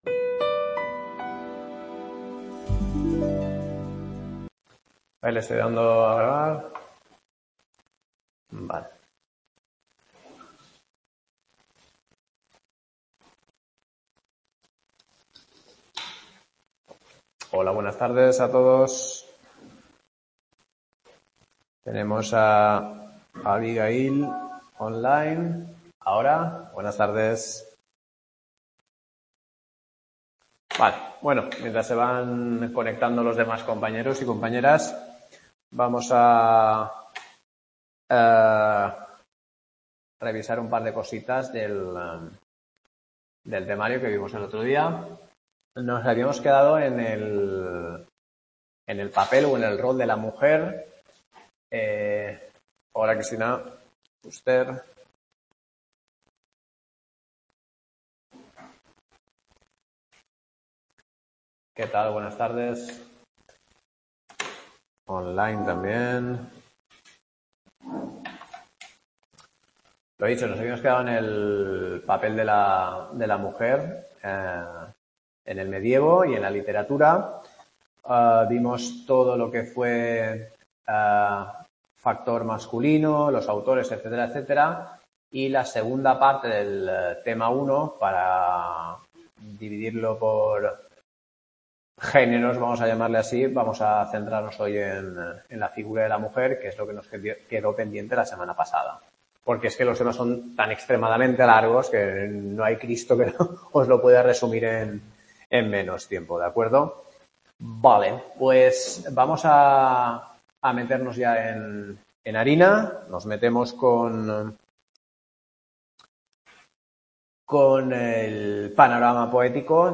LA MUJER EN EL MEDIEVO Clase 3